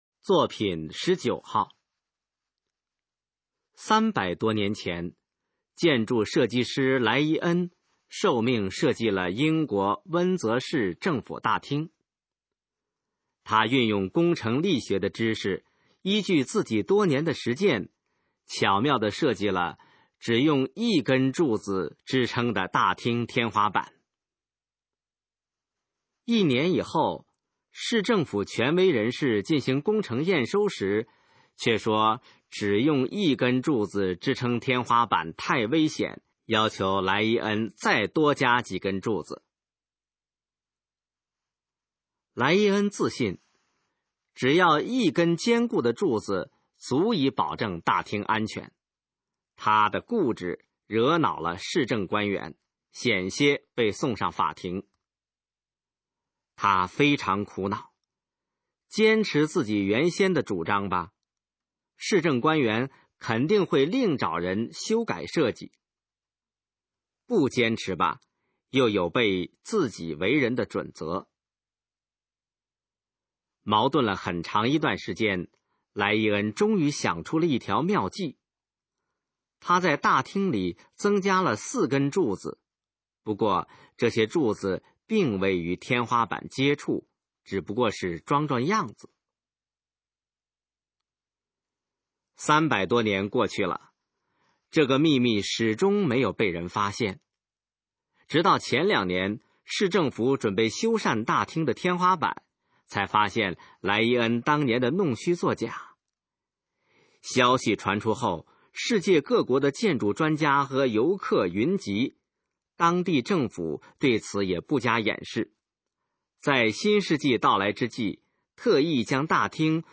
首页 视听 学说普通话 作品朗读（新大纲）
《坚守你的高贵》示范朗读_水平测试（等级考试）用60篇朗读作品范读